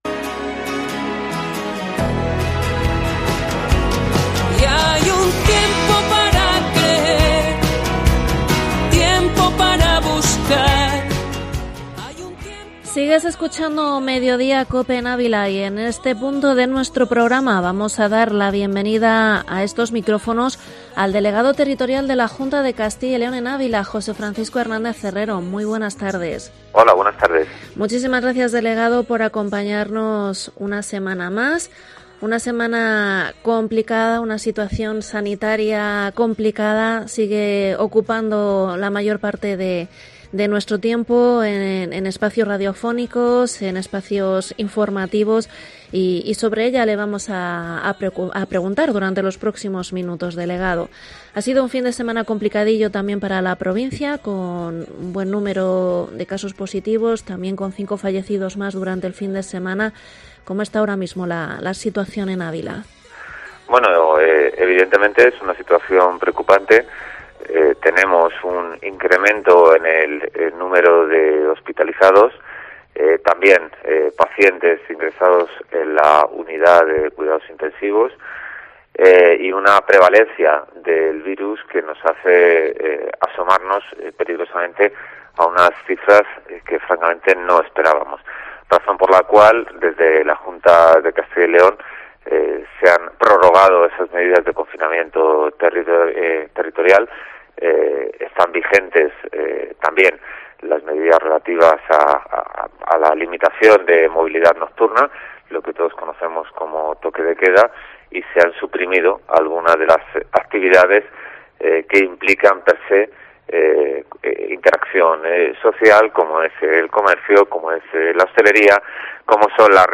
Entrevista delegado territorial, José Francisco Hernández Herrero 09/11/2020